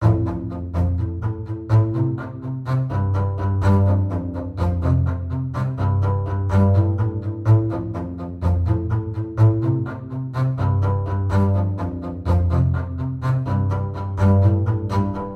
美丽的弦乐钢琴铜管乐Vol2ACOUSTIC BASS1
Tag: 125 bpm Chill Out Loops Bass Loops 2.81 MB wav Key : A